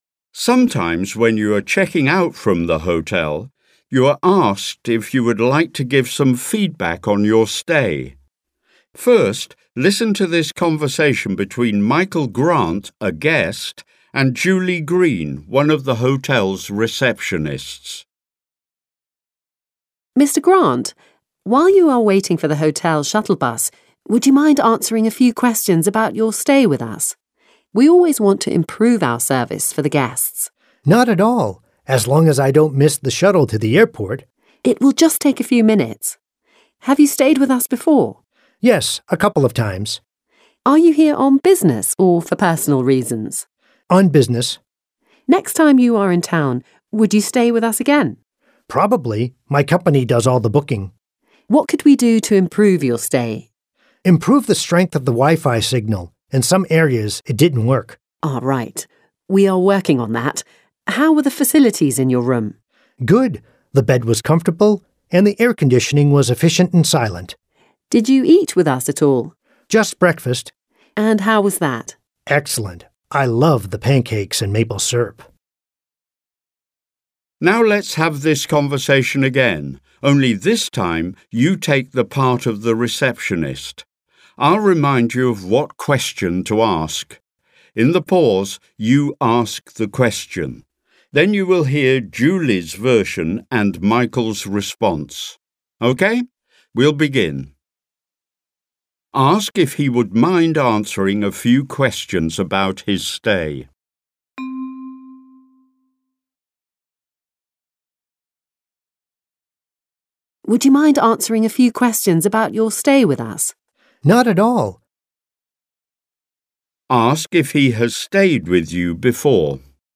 Exercise - Dialogue | ZSD Content Backend
Audio-Übung